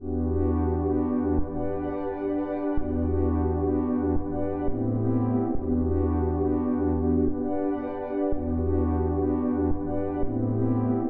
描述：一个垫环
Tag: 160 bpm Hip Hop Loops Pad Loops 2.02 MB wav Key : Am FL Studio